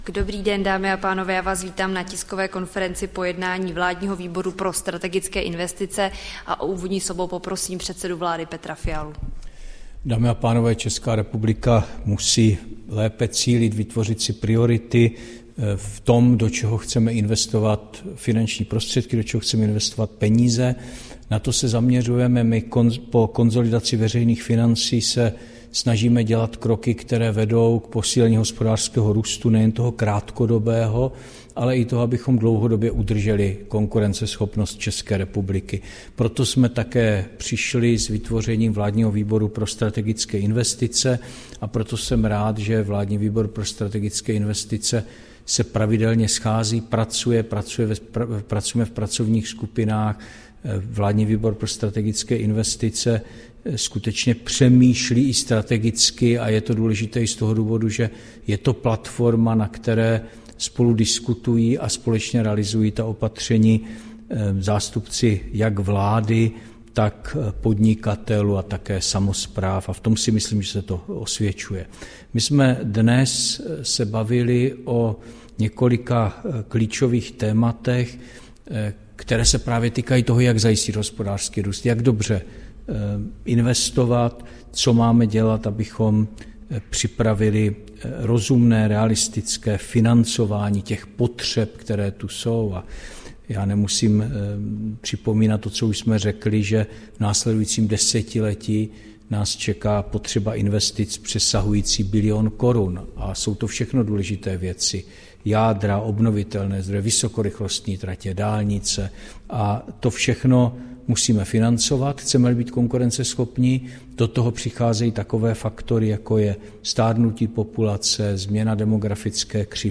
Tisková konference po zasedání Vládního výboru pro strategické investice, 1. července 2024